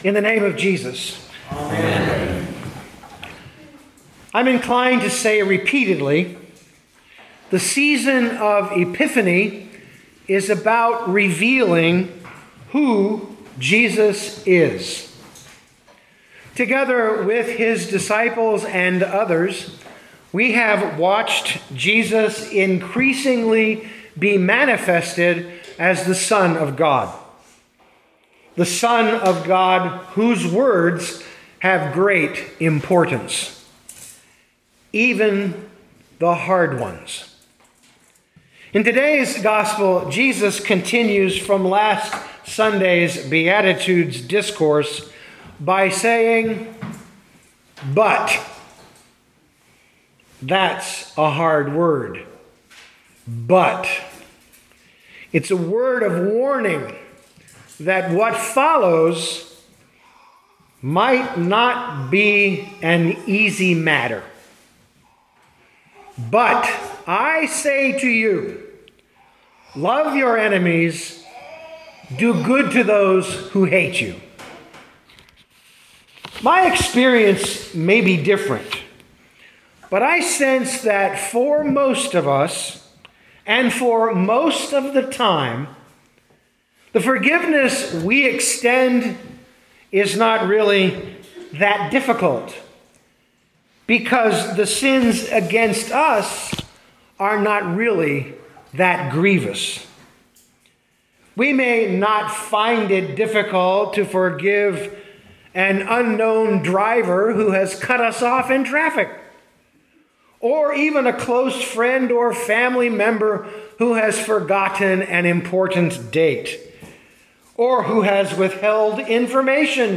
Luke 6:27-28 Listen to the sermon with the player below, or, download the audio.